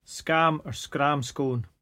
[scrAHm or scAHm scOHn]